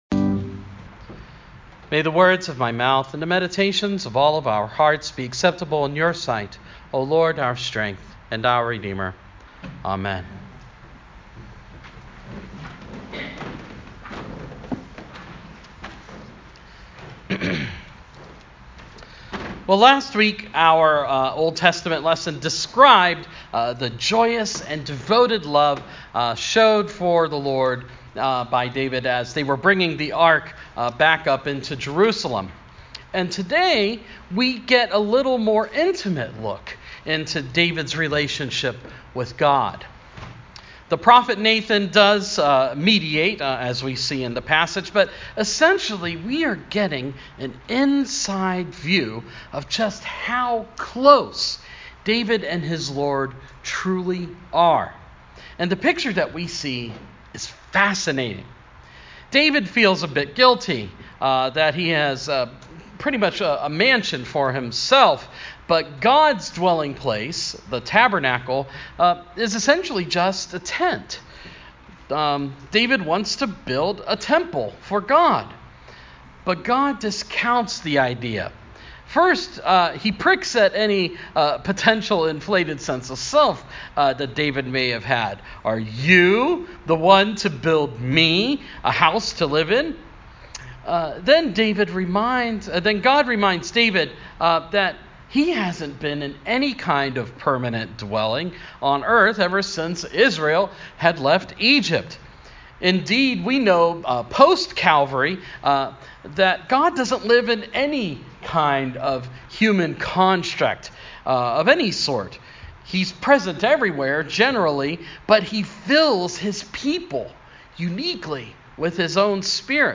Sermon – Proper 11